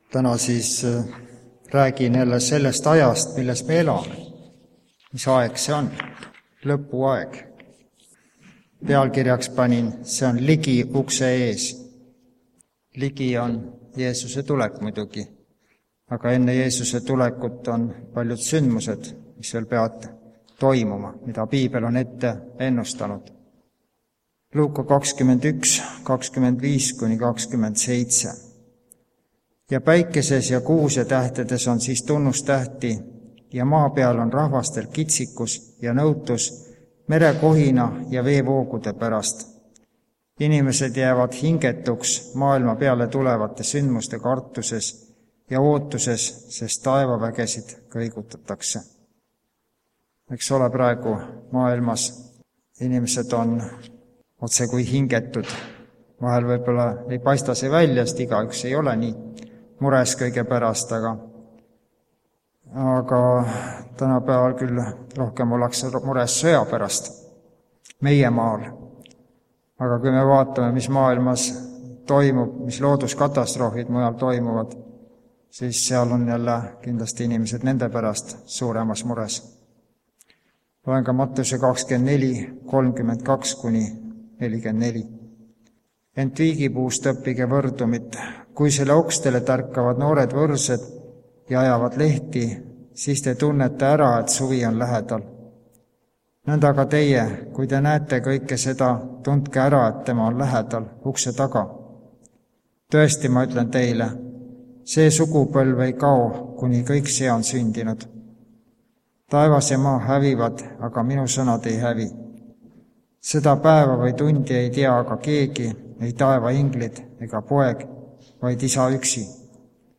Kuuleme ka instrumentaalmuusikat pereansamblilt
Jutlused